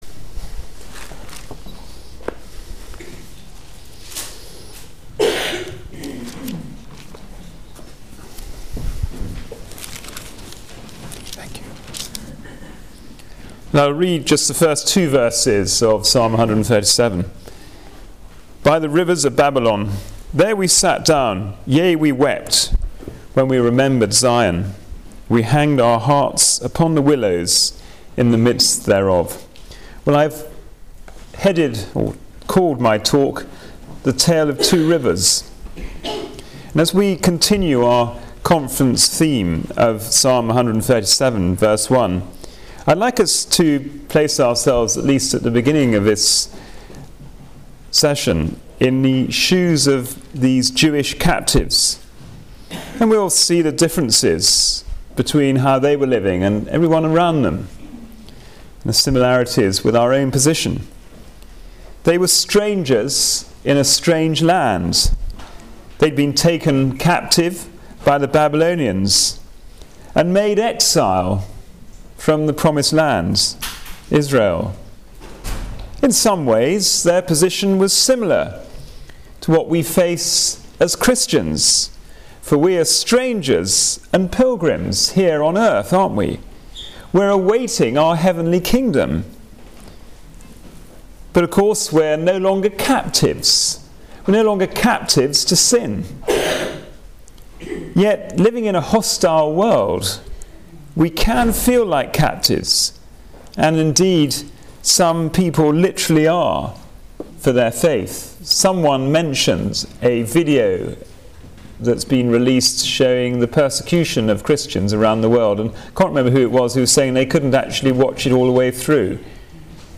Psalm 137:1-2 Passage: Psalm 137 Service Type: Christian Alliance Ministries Conference « ‘Where is the Lord God of Elijah?’